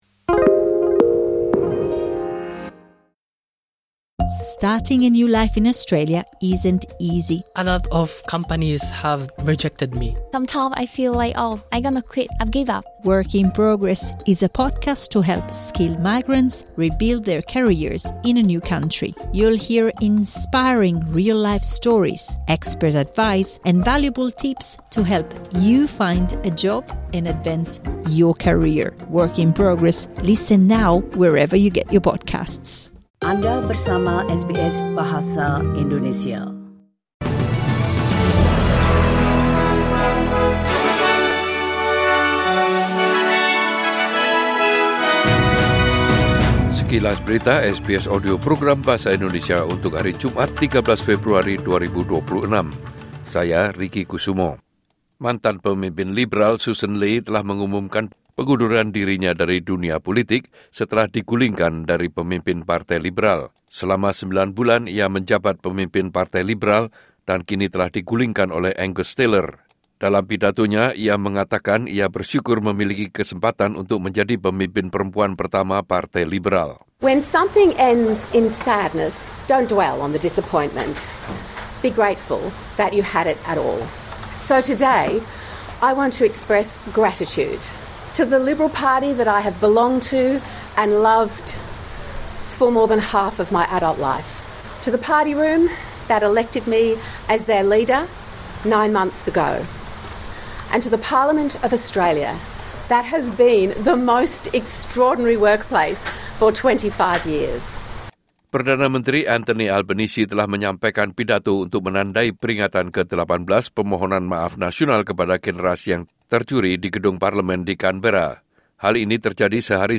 Berita terkini SBS Audio Program Bahasa Indonesia – Jumat 13 Februari 2026